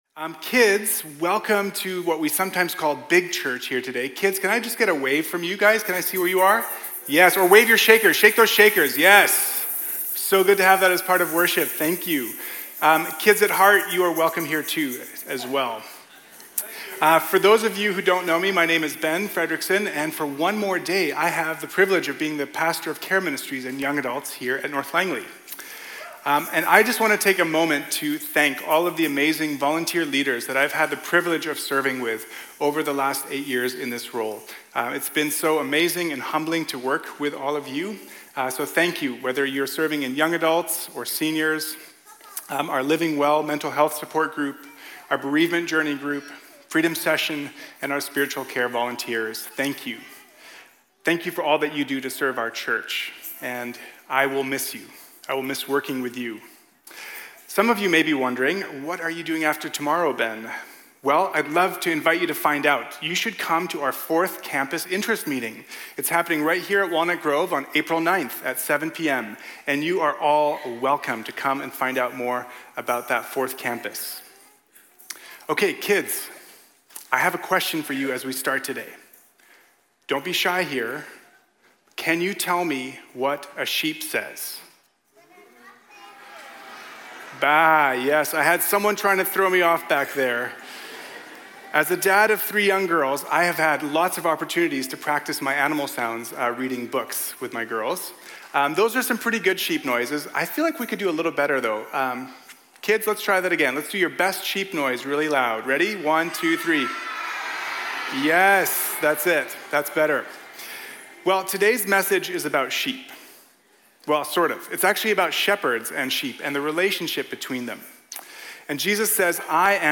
Walnut Grove Sermons | North Langley Community Church